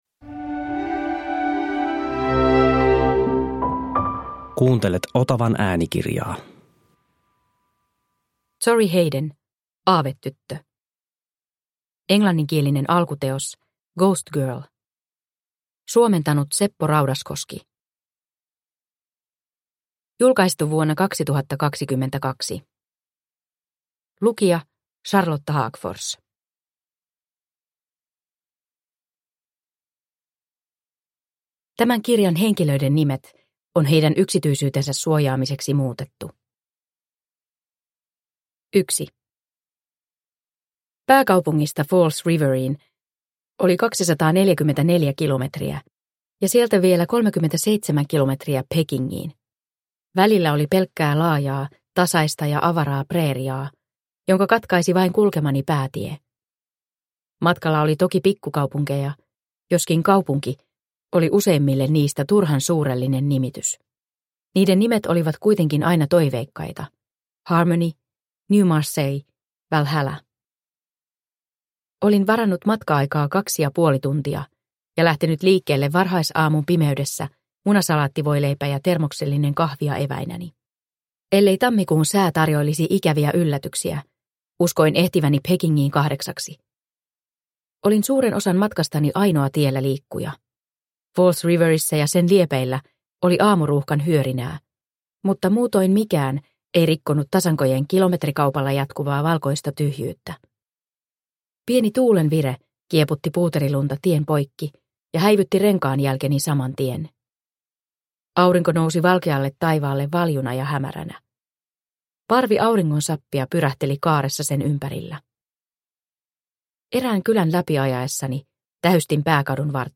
Aavetyttö – Ljudbok – Laddas ner